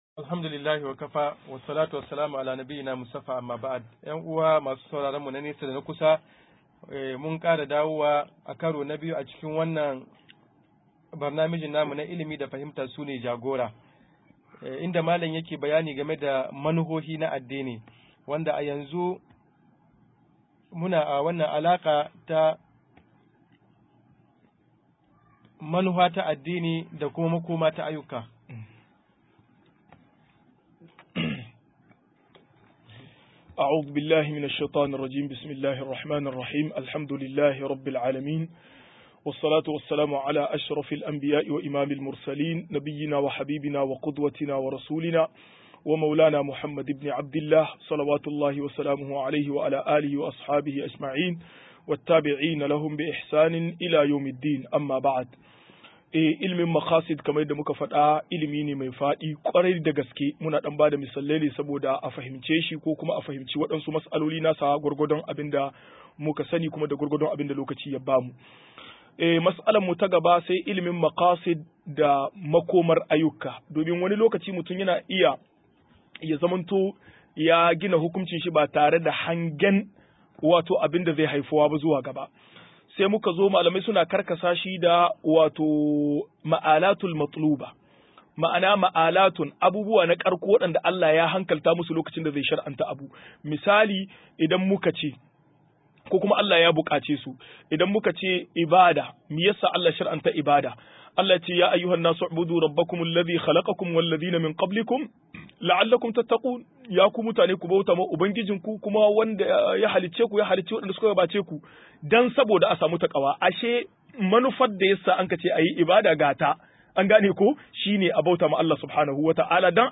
164-Ilimin Makasid Shari a 4 - MUHADARA